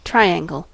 triangle: Wikimedia Commons US English Pronunciations
En-us-triangle.WAV